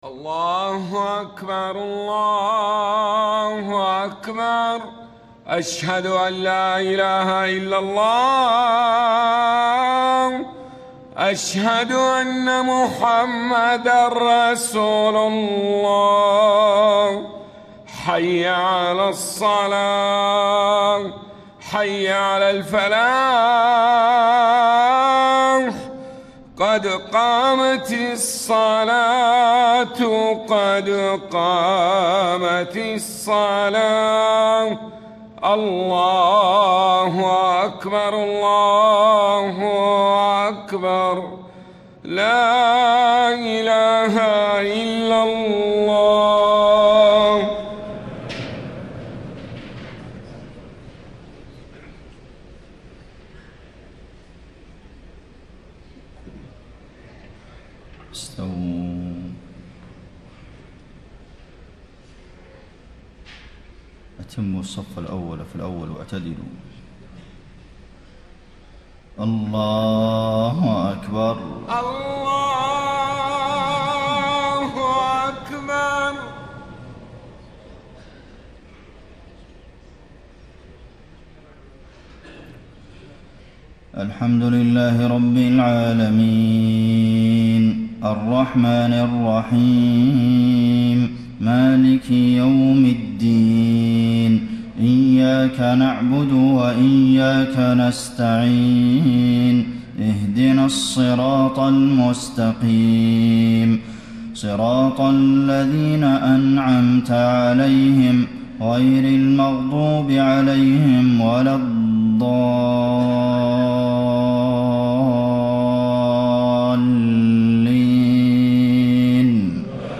فجر 6 رمضان ١٤٣٥ من سورة الحج > 1435 🕌 > الفروض - تلاوات الحرمين